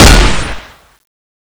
Index of /server/sound/weapons/tfa_cso/hk121_custom
fire_2.wav